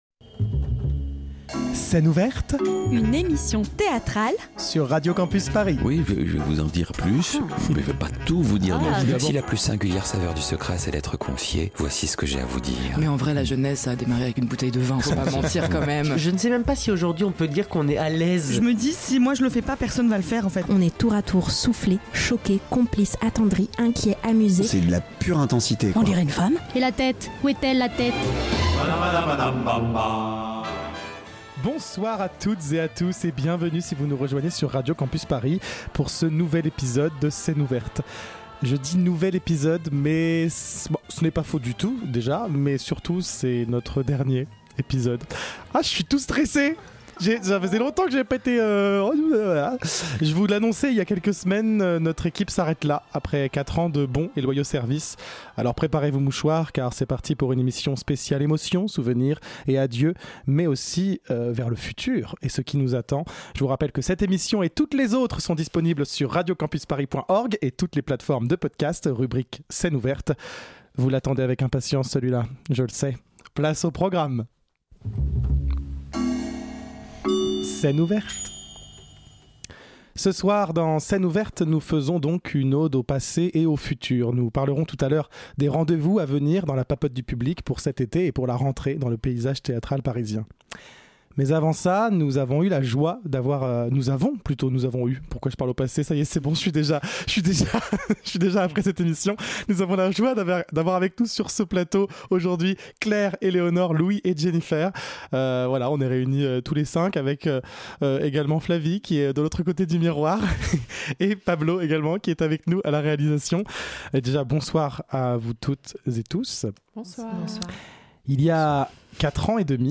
Pour cette dernière émission, nous vous proposons une séquence nostalgie avec nos meilleurs moments à l'antenne ces cinq dernières années.
// 17.06.2024 Partager Type Magazine Culture lundi 17 juin 2024 Lire Pause Télécharger Ce soir, Scène Ouverte baisse le rideau.